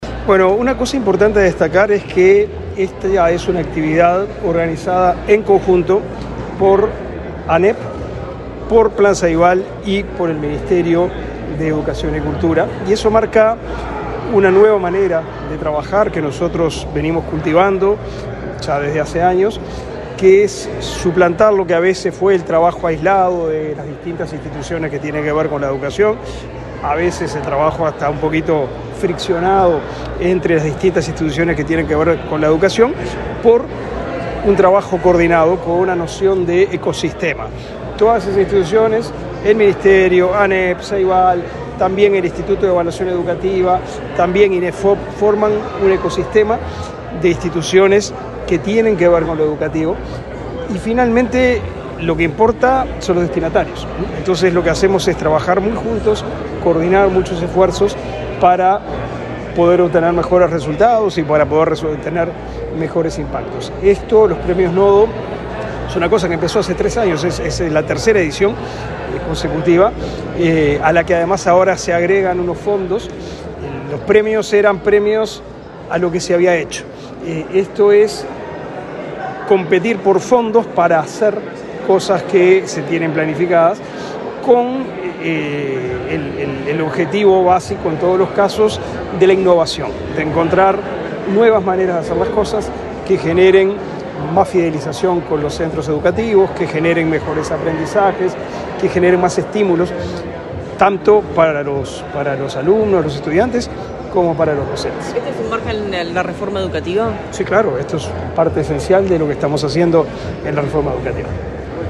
Declaraciones del ministro de Educación y Cultura, Pablo da Silveira
Declaraciones del ministro de Educación y Cultura, Pablo da Silveira 02/08/2023 Compartir Facebook X Copiar enlace WhatsApp LinkedIn El ministro de Educación y Cultura, Pablo da Silveira, dialogó con la prensa, antes de participar en el lanzamiento del Premio Nodo, impulsado por esa cartera, la Administración Nacional de Educación Pública y Ceibal.